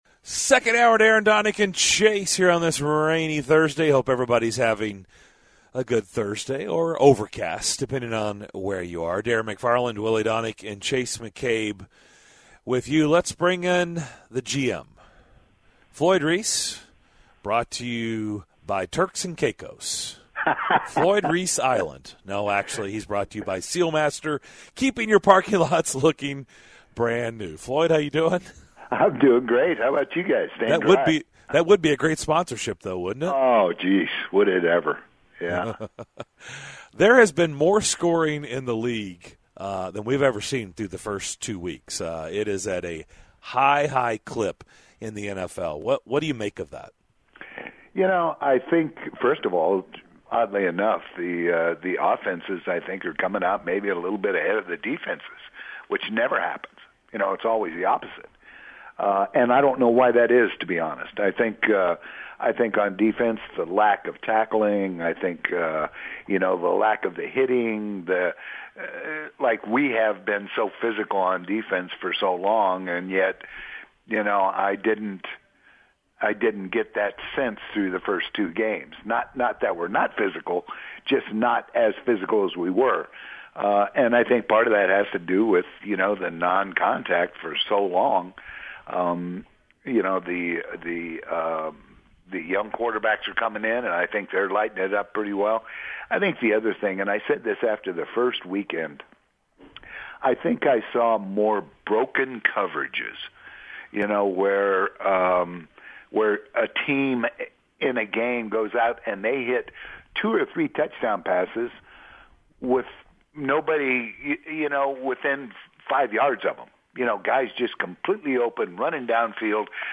In the second hour of Thursday's DDC: the guys talk with former Titans GM and co-host of Jared & The GM, Floyd Reese, listen in on Mike Vrabel's press conference, rank the 0-2 teams in the NFL and more!